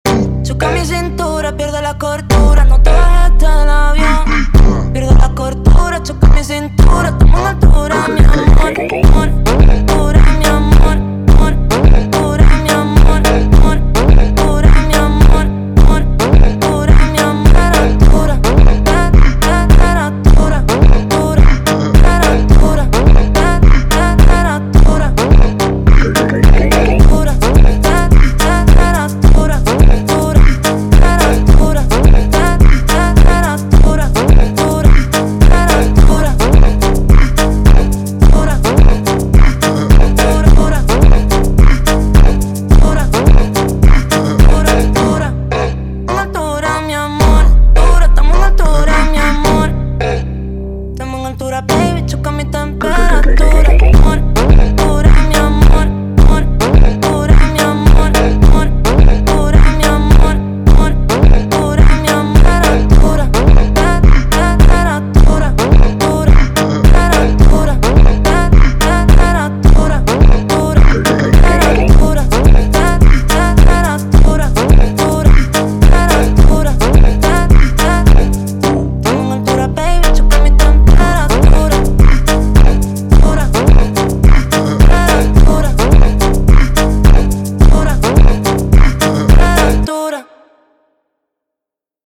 Трек размещён в разделе Зарубежная музыка / Фонк.